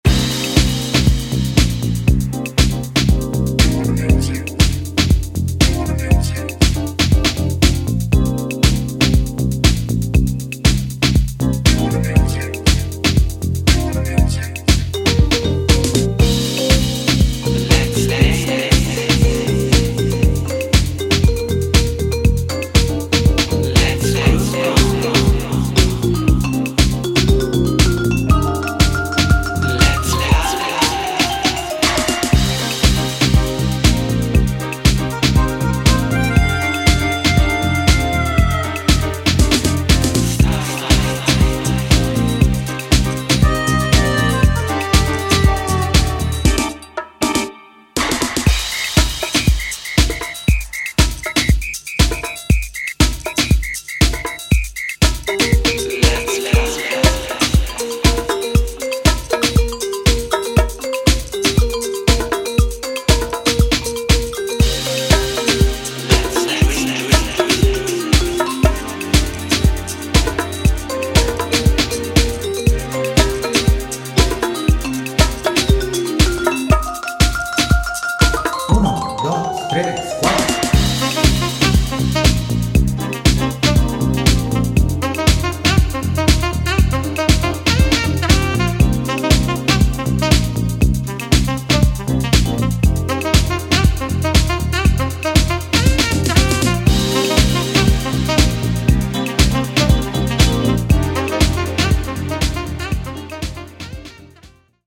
he presents a nostalgic vision of twilight synth-pop